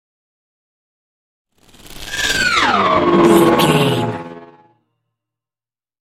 Sci fi vehicle whoosh large
Sound Effects
dark
futuristic
whoosh